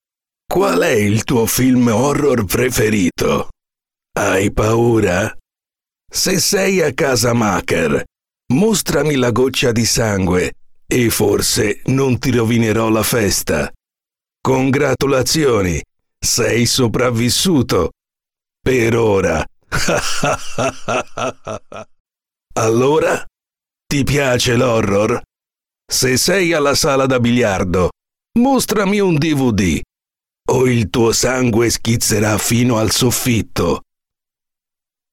Profonde, Chaude, Corporative, Commerciale, Senior, Mature